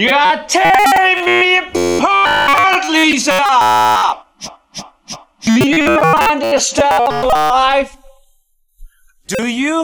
Stuttering Butcher applies stutter edits and glitch on an audio file according to settings.
theroom1-glitch.wav